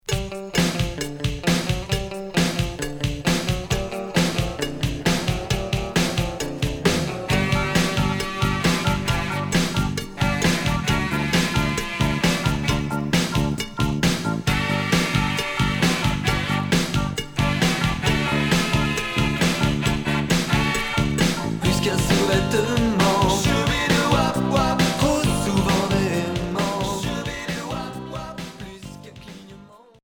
Ska new wave Unique 45t retour à l'accueil